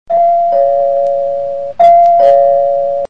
门铃铃声二维码下载